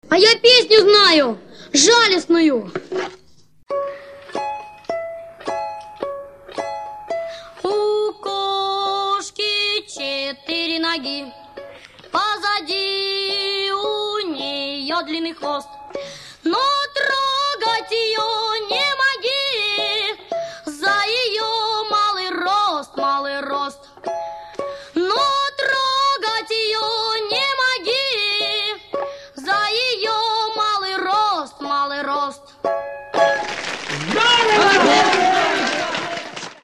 1:1, фон и жалистная песня...